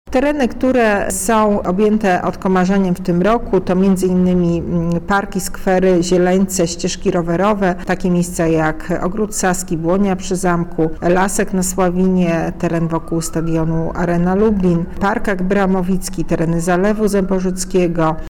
• Mówi